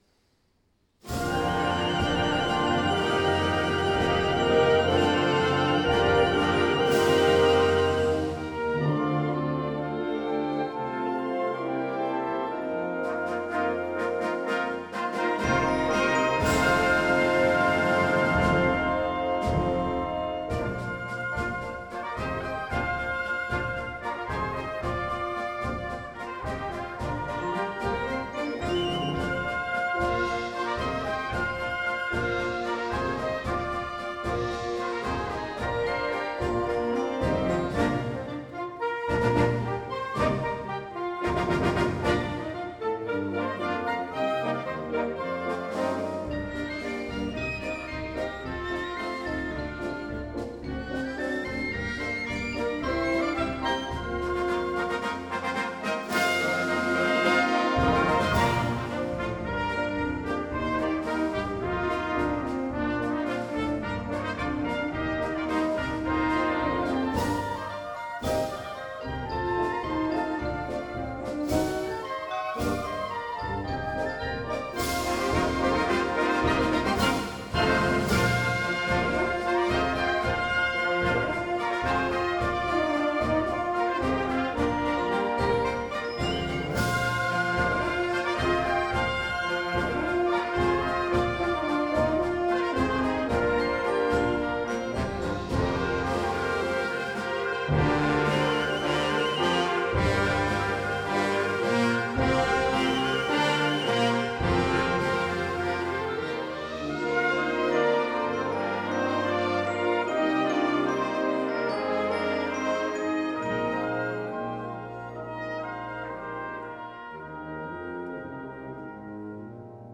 Basiert auf ein chinesisches Lied.
Besetzung: Concert Band